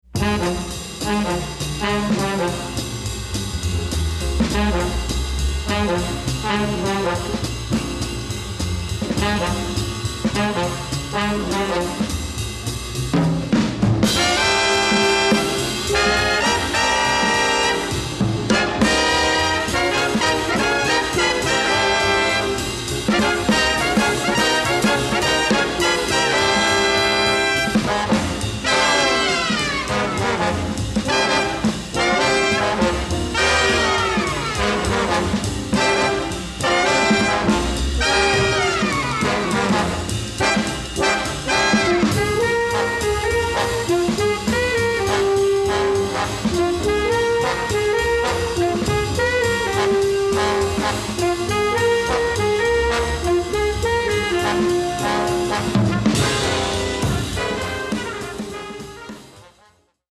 jazz-infused roller-coaster of a score